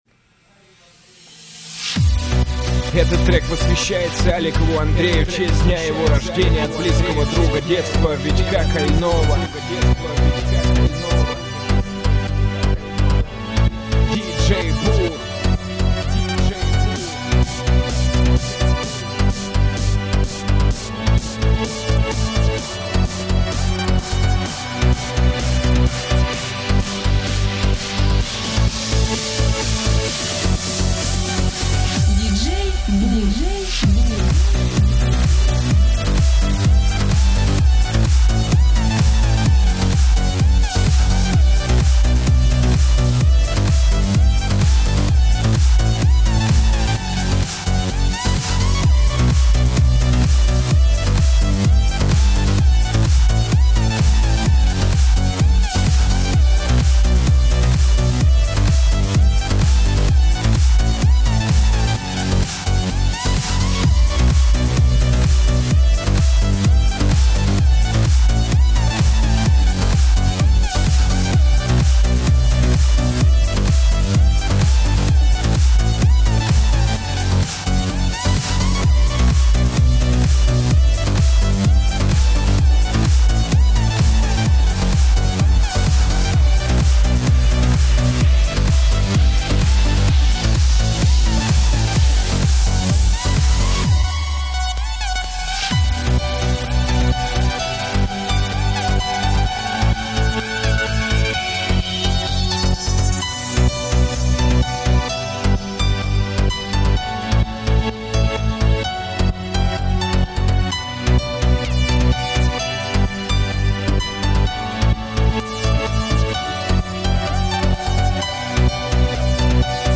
Категория: Trance